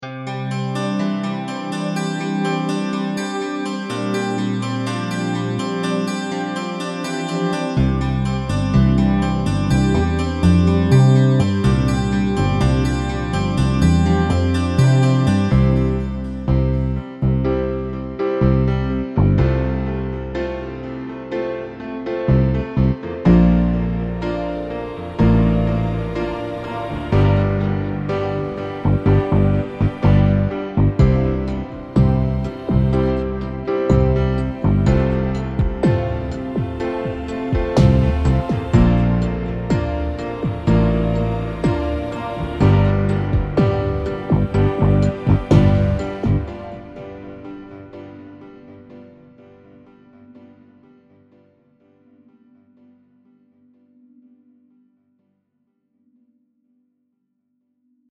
Auch wenn der metallische Eigenklang nicht jedermanns Geschmack trifft, ist der Sound sicherlich nicht schlecht und fügt sich gut in vorhandene Mischungen ein.
Acoustic Pop
camel_audio_alchemy_testbericht_04_acoustic_pop_-_5_instanzen.mp3